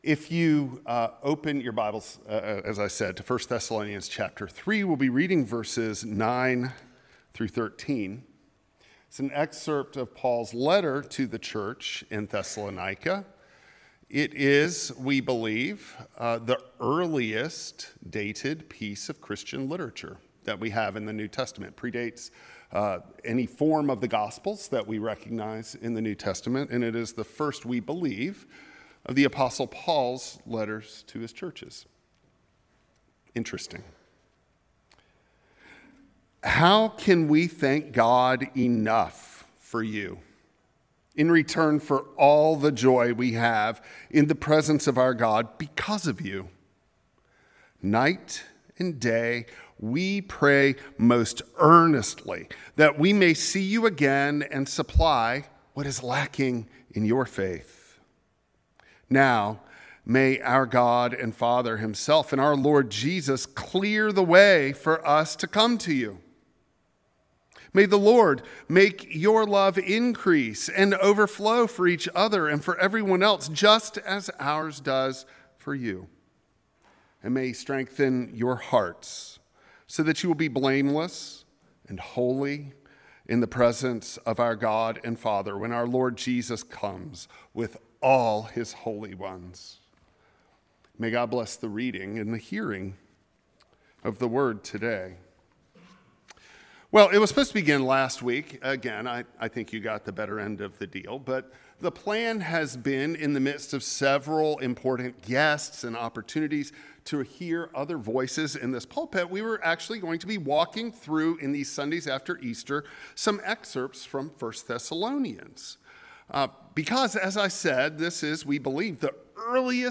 1 Thessalonians 3:9-13 Service Type: Traditional Service Affection